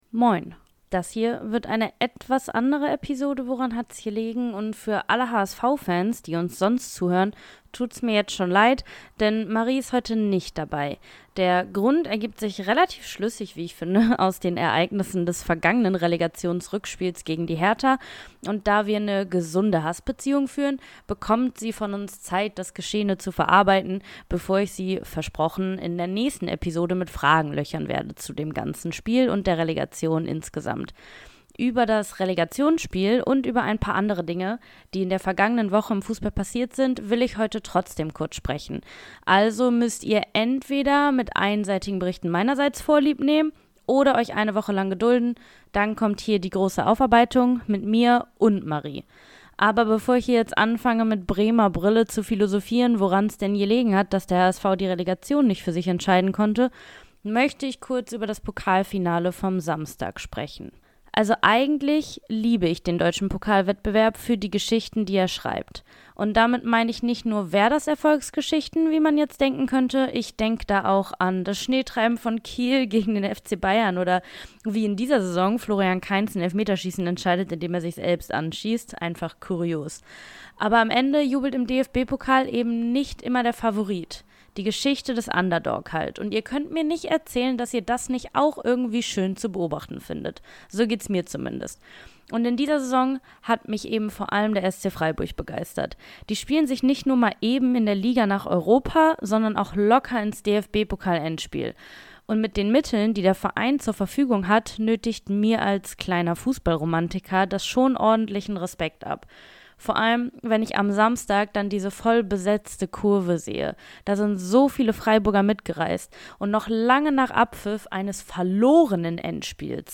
Der unprofessionellste Fußballtalk Deutschlands